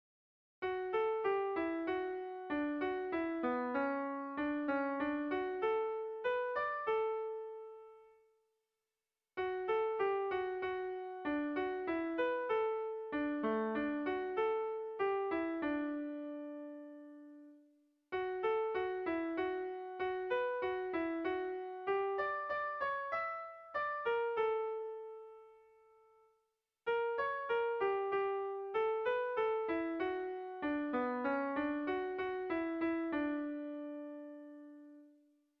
Air de bertsos - Voir fiche   Pour savoir plus sur cette section
Zortziko handia (hg) / Lau puntuko handia (ip)
ABDE